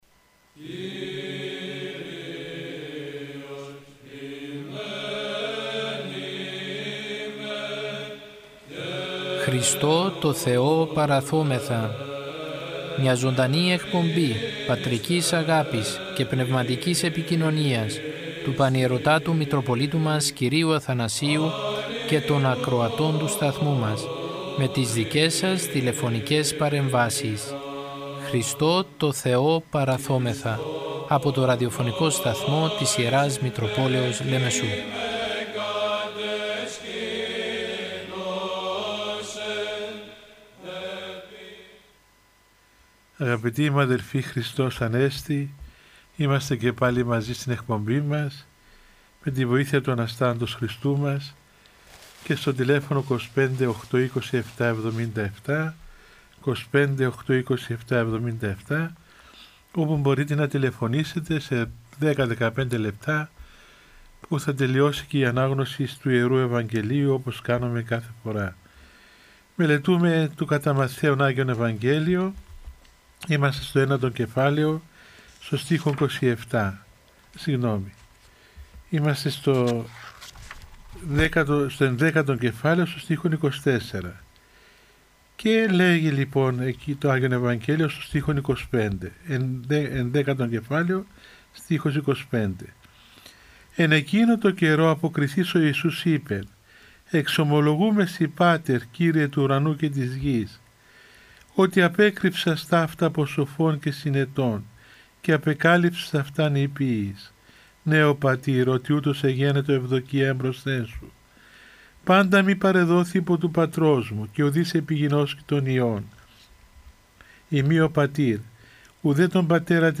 Η Ιερά Μητρόπολη Λεμεσού μετά από το διάταγμα που εξέδωσε η Κυβέρνηση για την αντιμετώπιση της πανδημίας του κορωνοϊού που περιορίζει τις μετακινήσεις και την προσέλευση των πιστών στους ναούς, θέλοντας να οικοδομήσει και να στηρίξει ψυχικά και πνευματικά όλους τους πιστούς παρουσιάζει καθημερινά ζωντανές εκπομπές με τον Πανιερώτατο Μητροπολίτη Λεμεσού κ. Αθανάσιο, με τίτλο «Χριστώ τω Θεώ παραθώμεθα». Ο Πανιερώτατος πραγματοποιεί απαντά στις τηλεφωνικές παρεμβάσεις των ακροατών του Ραδιοφωνικού Σταθμού της Ι. Μ. Λεμεσού και απευθύνει λόγο παρηγορητικό, παραμυθητικό και ποιμαντικό.